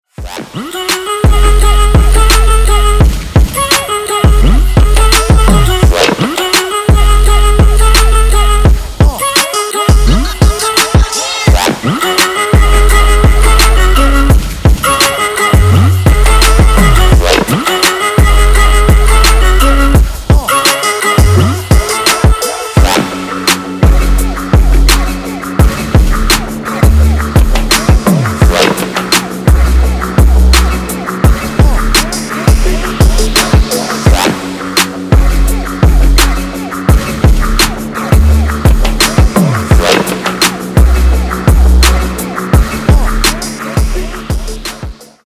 • Качество: 192, Stereo
EDM
Trap
Bass
drop